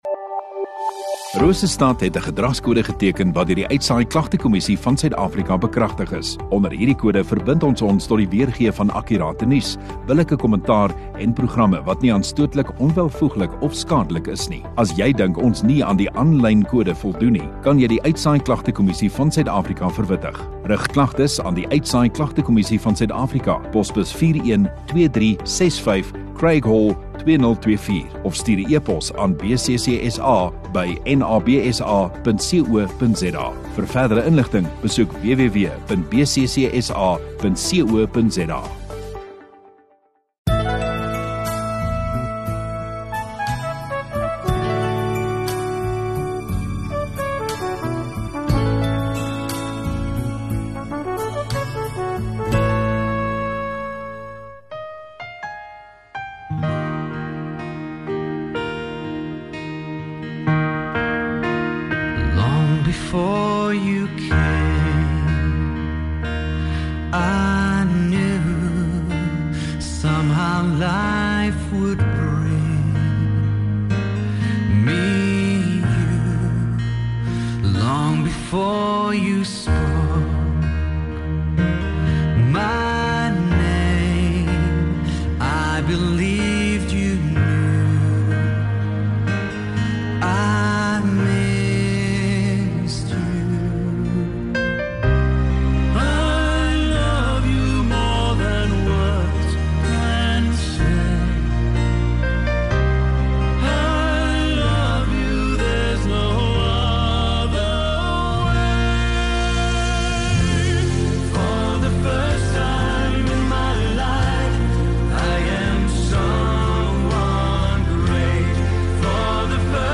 16 Jun Sondagoggend Erediens